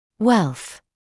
[welθ][уэлс]богатство; изобилие